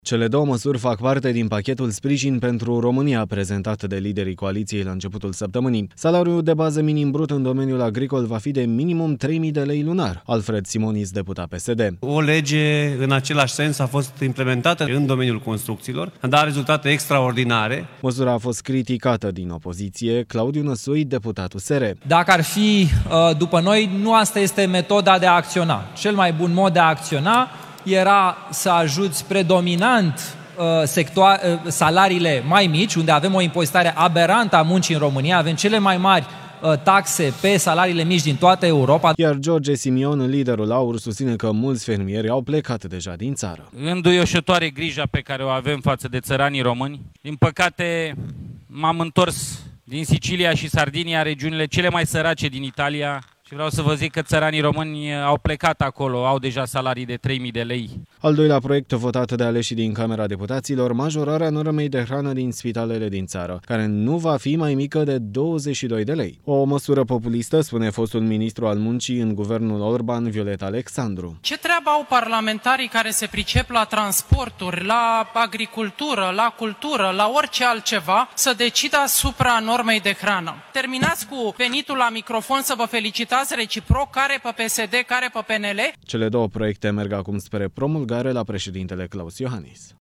Dezbateri aprinse astăzi în Camera Deputaților: fără mari probleme pentru coaliția de guvernare, aleșii au trecut astăzi proiectele privind majorarea salariului minim în agricultură la 3000 de lei și creșterea normei de hrană din spitale.